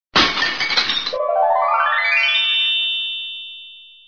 BreakEgg.wav